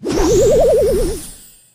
ruff_ulti_throw_01.ogg